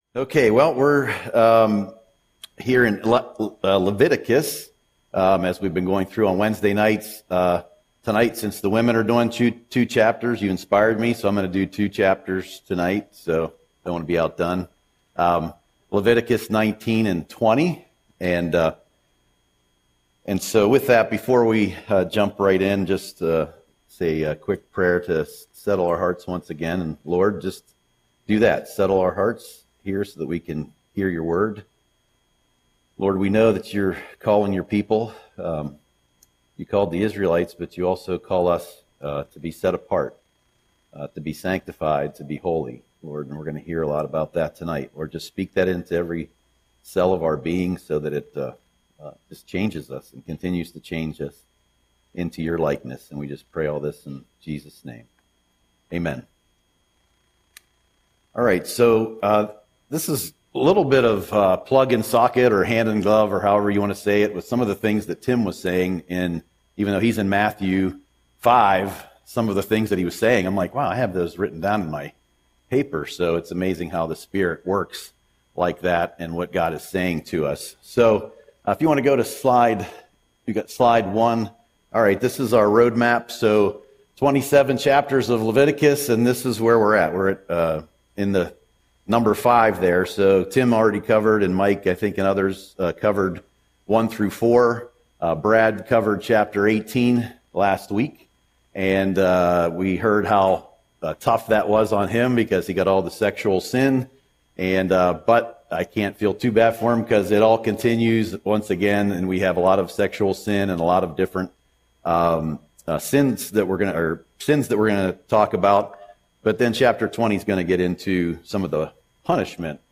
Audio Sermon - December 10, 2025